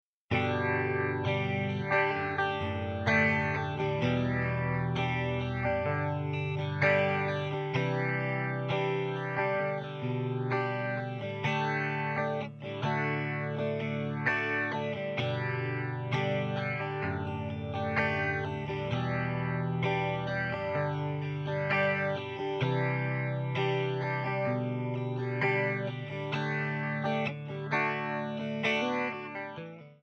Backing track Karaoke